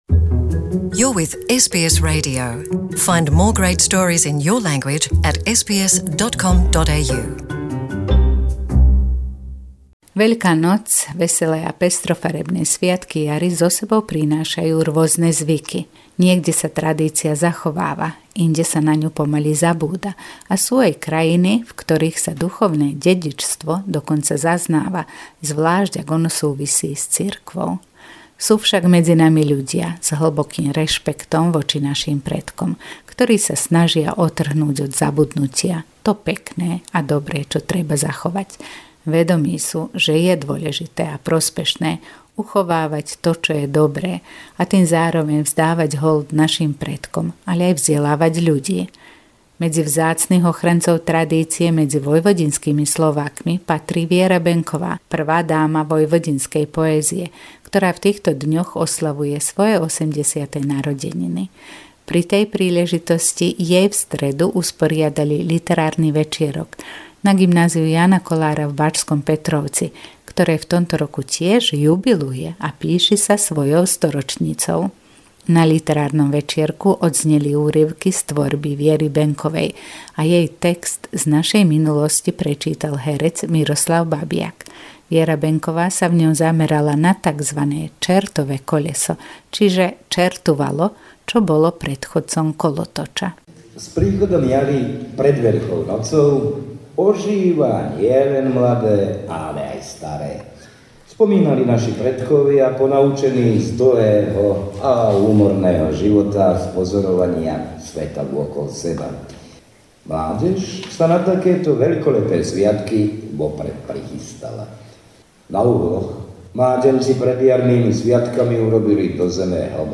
Zvuková pohľadnica